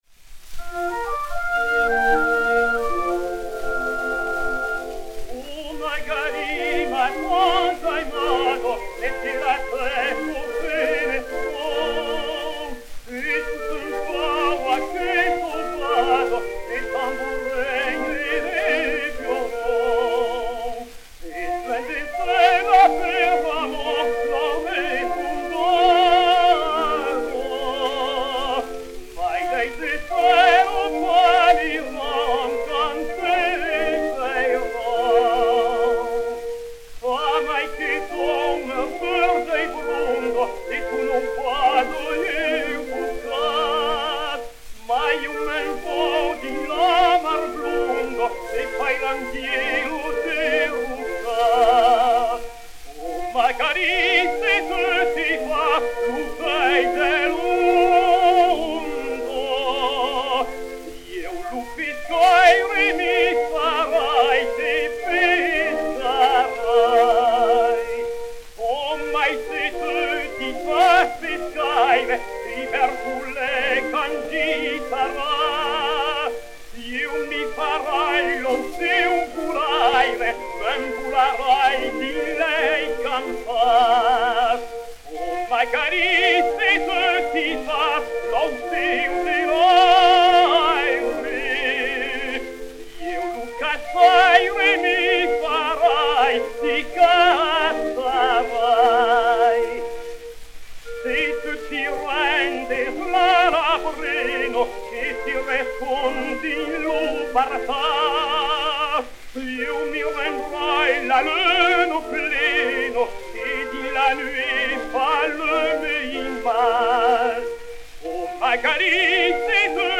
chanson provençale tirée du poème Mireille, transcrite par Seguin
Léon Campagnola et Orchestre
Disque Pour Gramophone 032251, réédité sur Y 48 et W 671, enr. à Paris le 13 novembre 1911